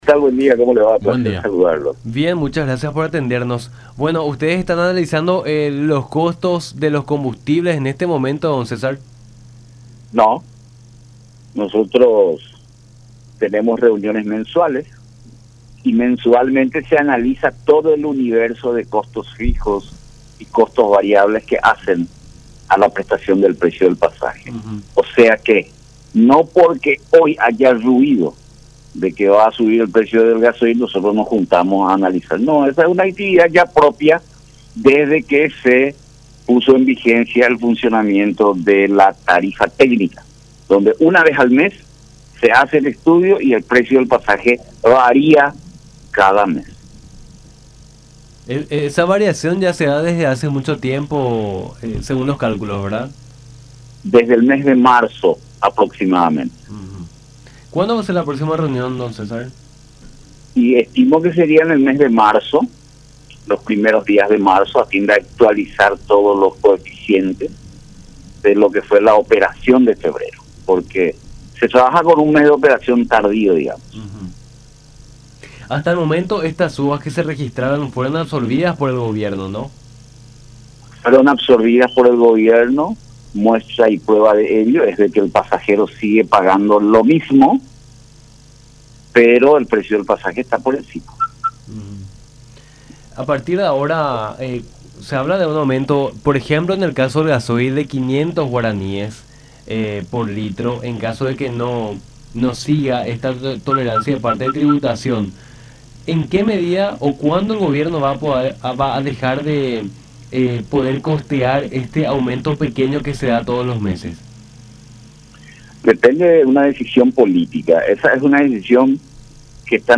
en conversación con Nuestra Mañana